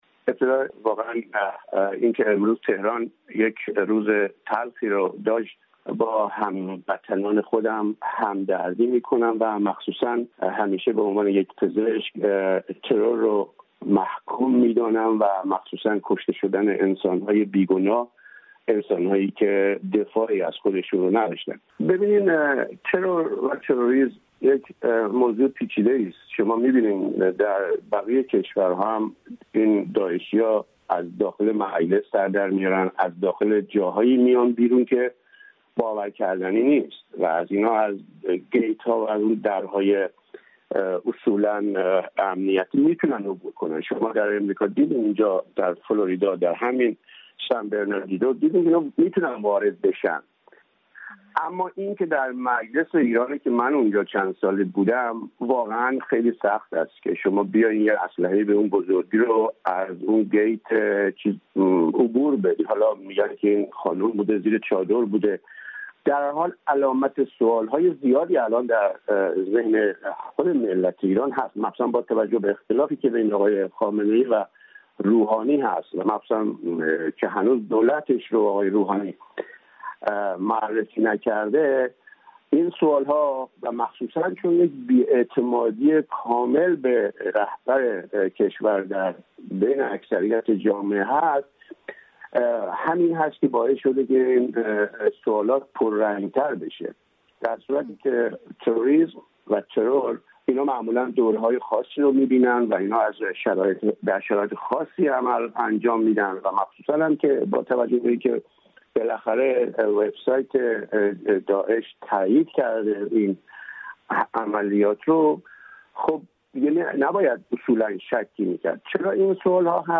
در گفت‌وگوی پیش رو آقای پیرموذن از تدابیر امنیتی در ساختمان مجلس و همچنین عواقب حمله روز چهارشنبه می‌گوید.